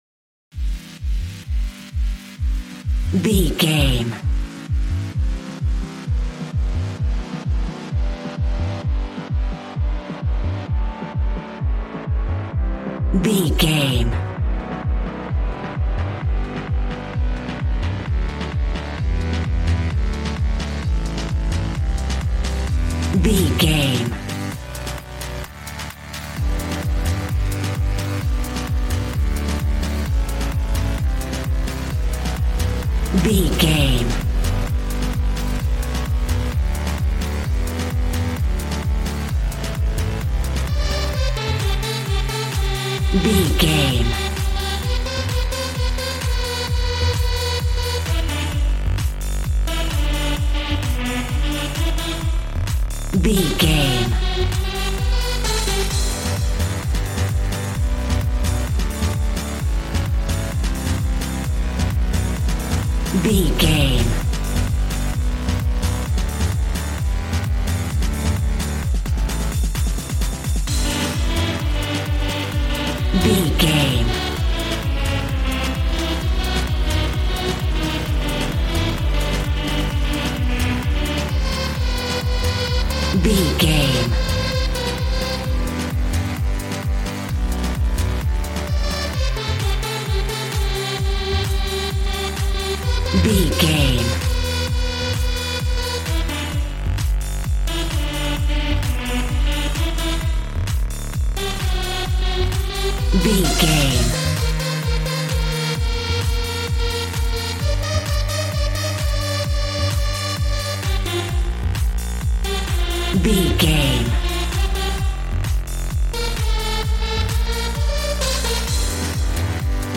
Aeolian/Minor
Fast
uplifting
lively
groovy
synthesiser
drums